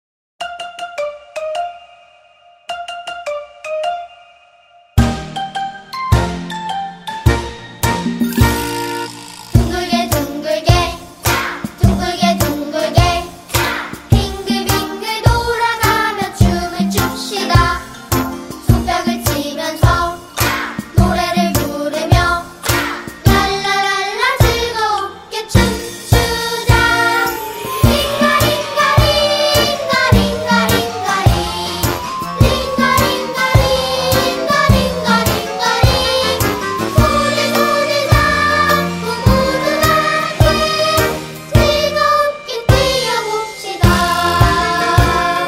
инструментальные электронные